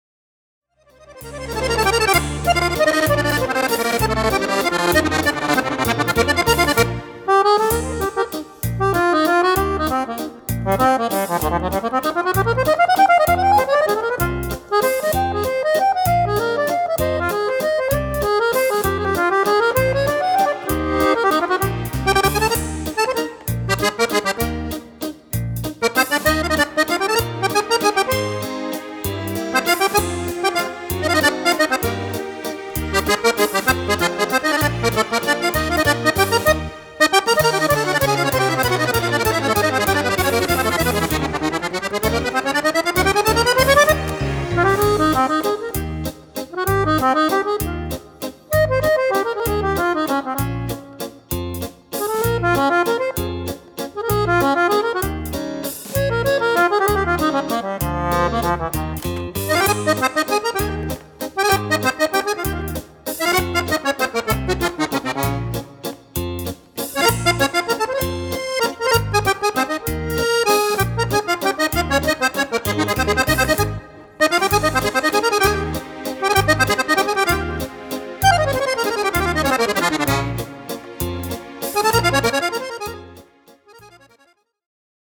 Valzer
Fisarmonica
Strumento Fisarmonica (e Orchestra)